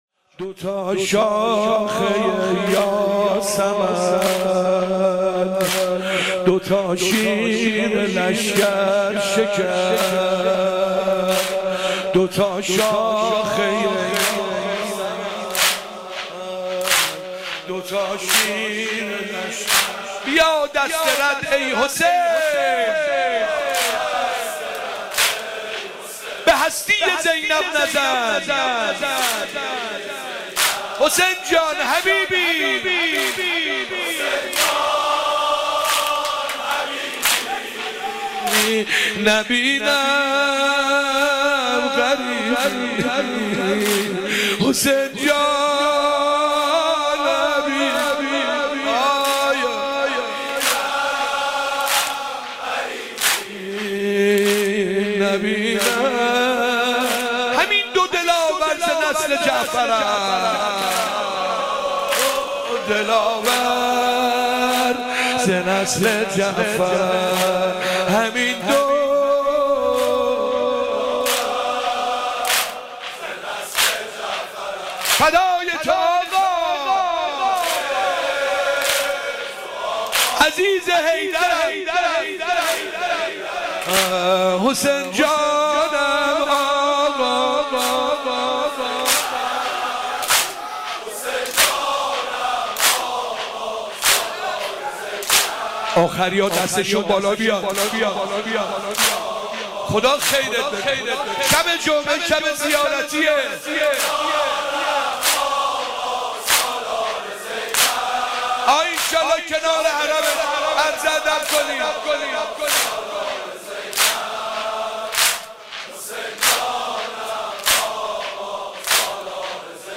غزل و روضه
نوحه شب چهارم محرم